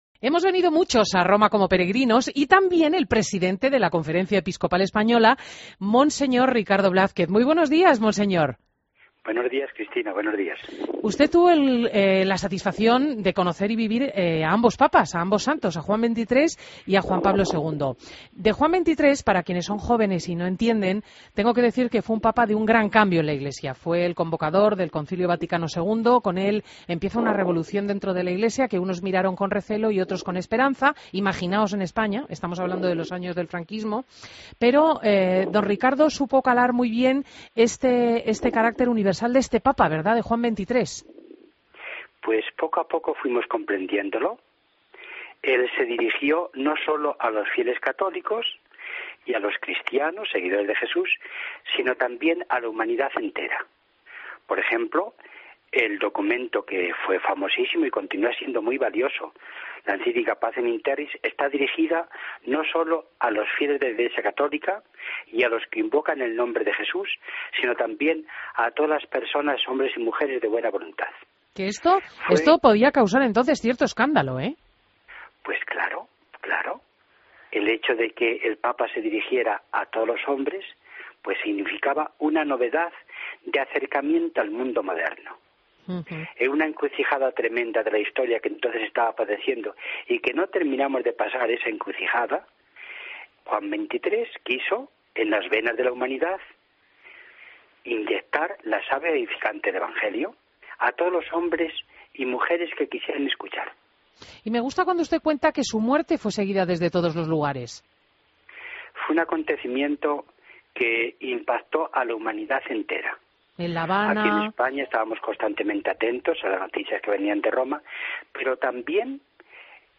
Entrevista a Ricardo Blázquez en Fin de Semana COPE